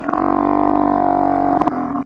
Mutant Goat Bleet.wav